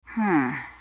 hmm1.wav